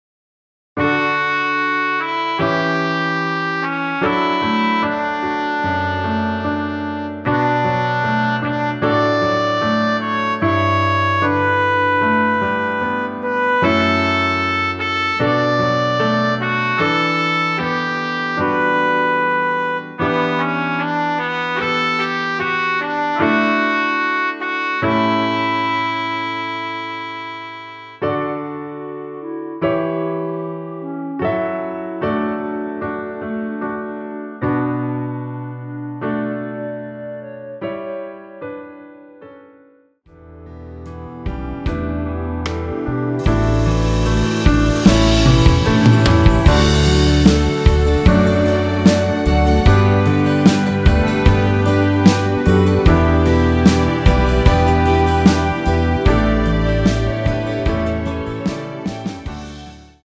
발매일 1995.05 원키에서(+2) 올린 멜로디 포함된 MR 입니다.(미리듣기 참조)
노래방에서 노래를 부르실때 노래 부분에 가이드 멜로디가 따라 나와서
앞부분30초, 뒷부분30초씩 편집해서 올려 드리고 있습니다.
중간에 음이 끈어지고 다시 나오는 이유는